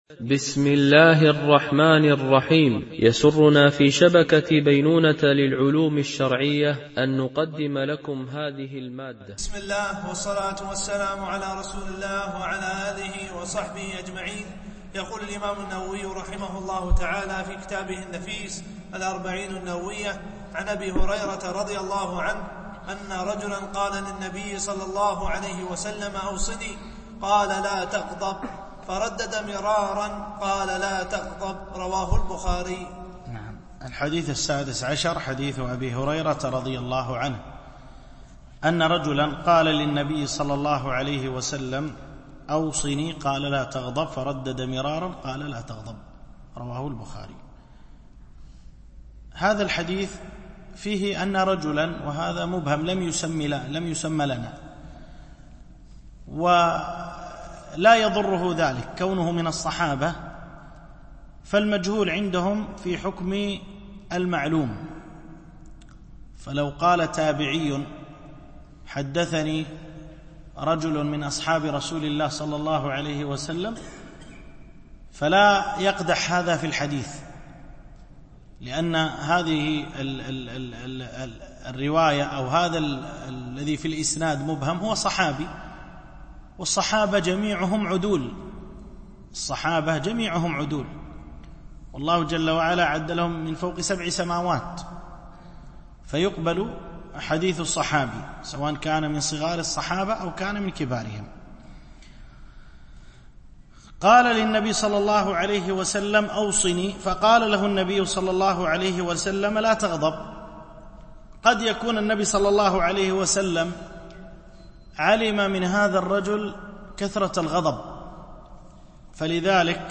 شرح الأربعين النووية - الدرس 9 (الحديث 16-17-18)